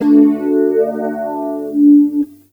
C GTR 3.wav